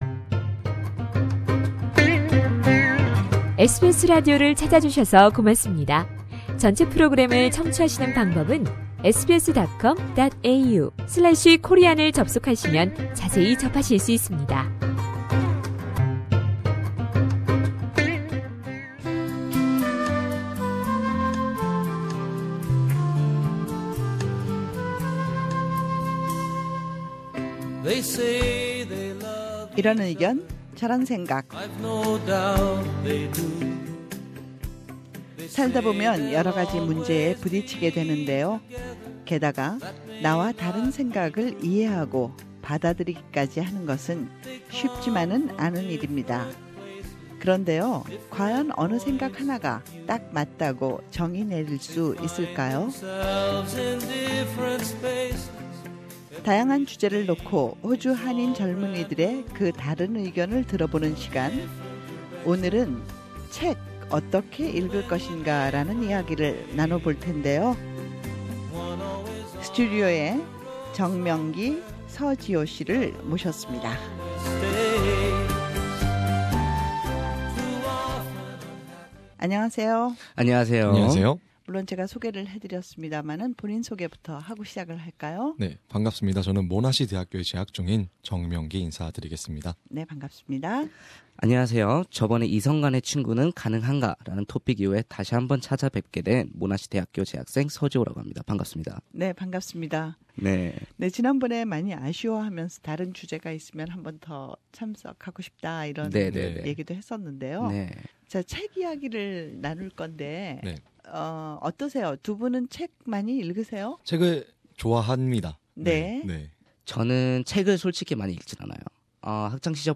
우리 주변의 다양한 이슈에 대해 호주에 사는 한인 청년들은 어떤 생각을 갖고 있을까요? '이런 생각, 저런 의견' 이번 주 토론 주제는종이책? 혹은ebook?' 입니다.